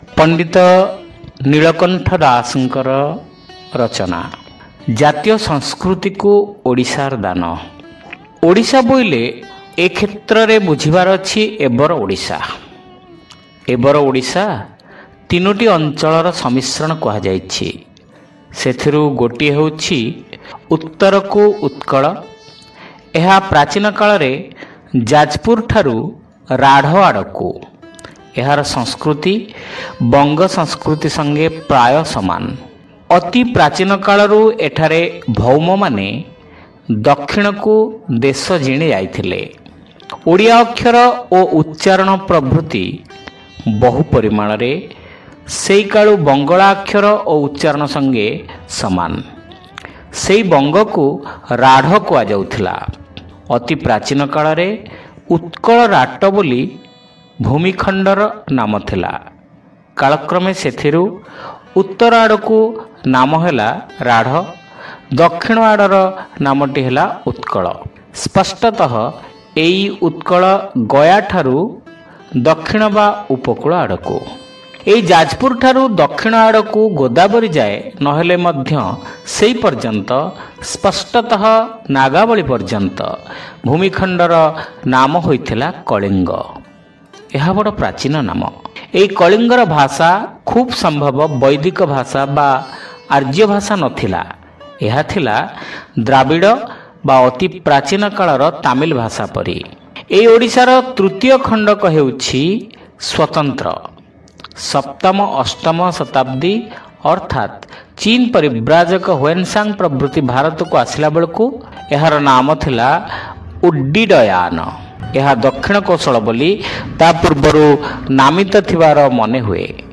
ଶ୍ରାବ୍ୟ ଗଳ୍ପ : ଜାତୀୟ ସଂସ୍କୃତିକୁ ଓଡ଼ିଶାର ଦାନ